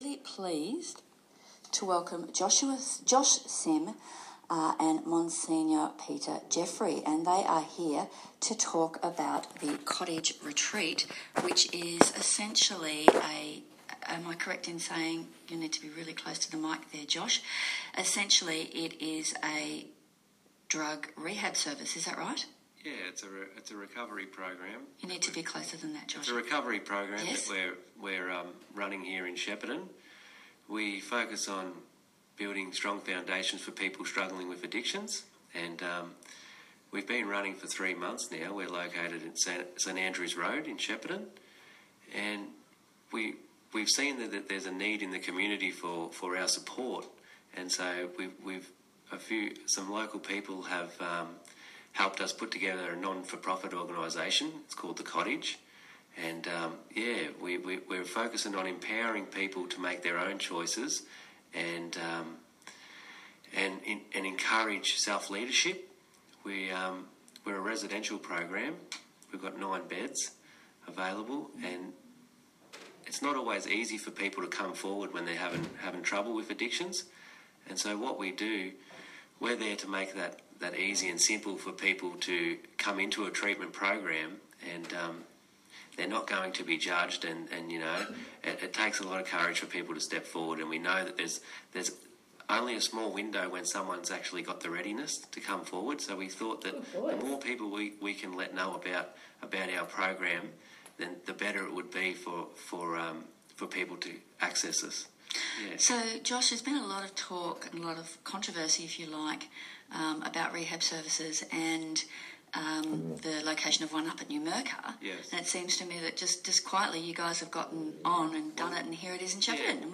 ONE FM Interview with The Cottage